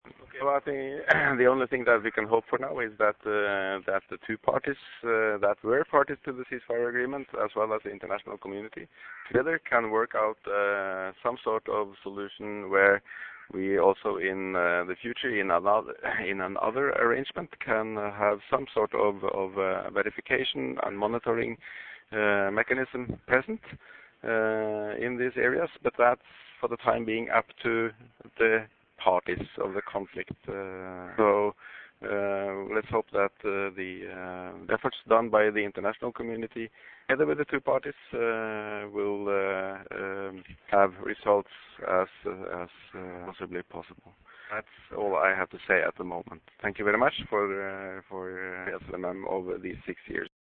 SLMM HoM Maj. Gen. Lars Johan Solvberg addressing media
Voice: Press briefing by the Head of SLMM